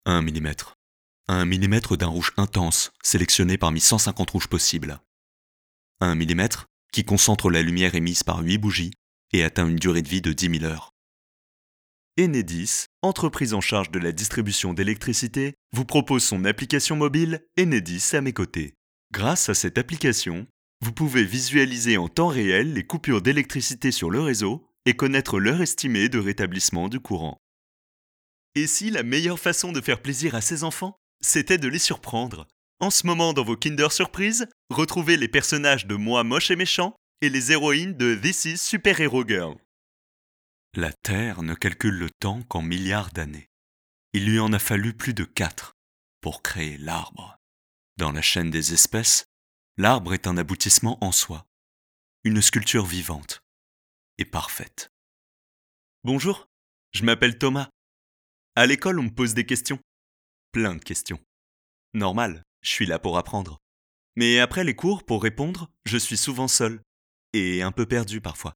Voix off
Bande Démo Voix-Off